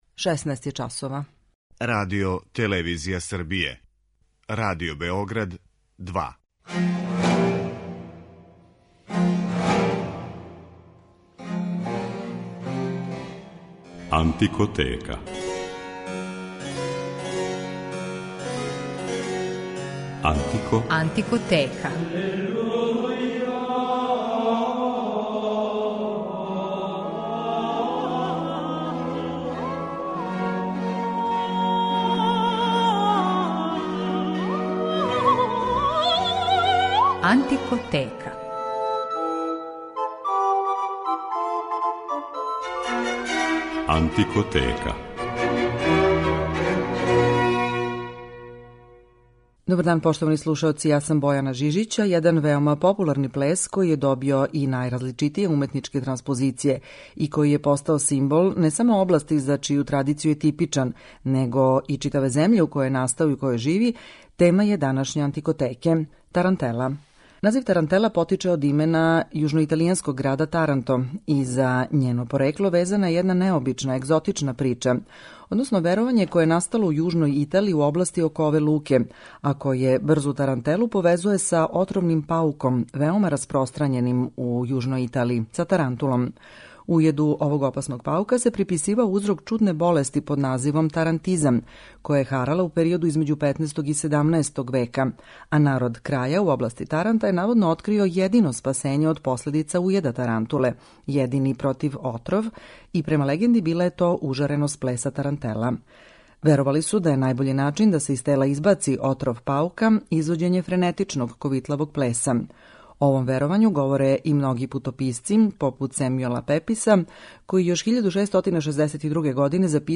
Слушаћете јужноиталијанску игру тарантелу, и то у многим њеним варијантама и облицима.